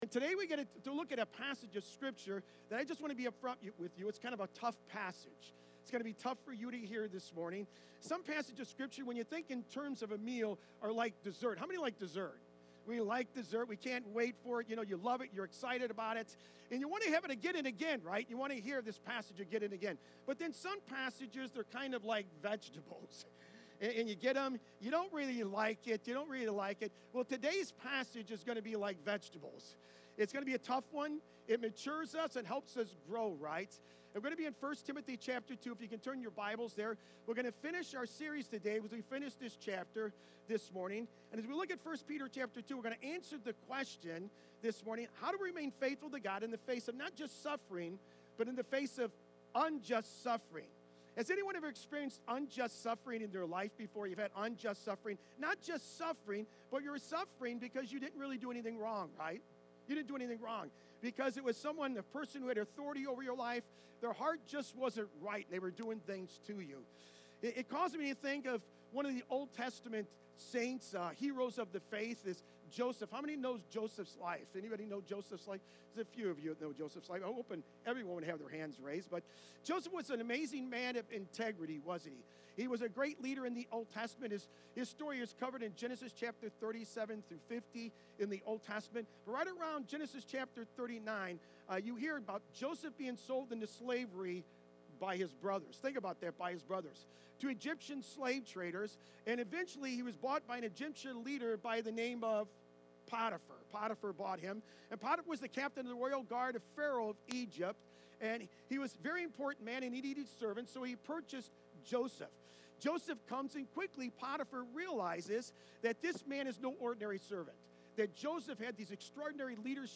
Sermons
3-2-25-sermon.mp3